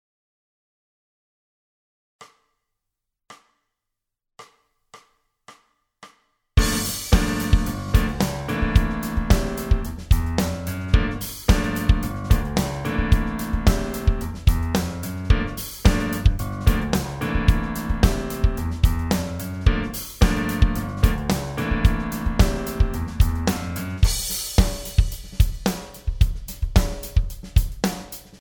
Funk `n` Soul Riff 11